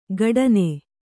♪ gaḍane